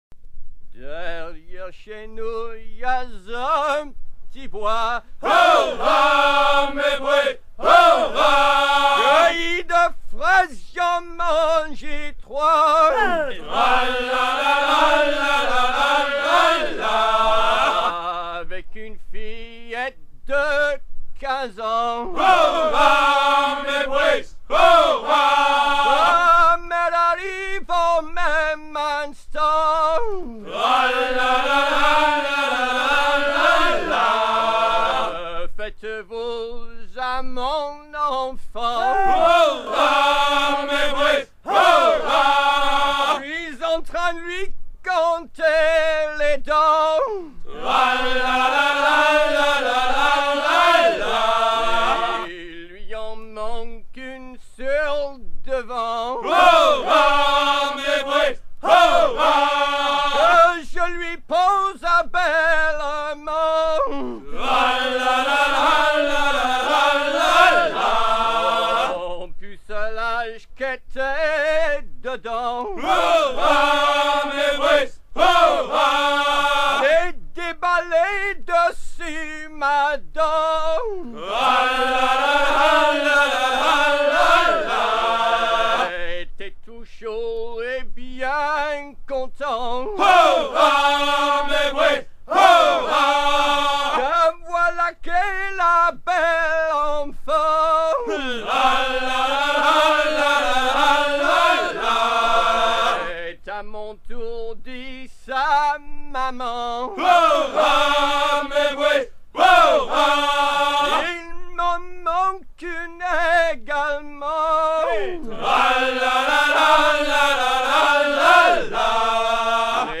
gestuel : à hisser à grands coups
Genre laisse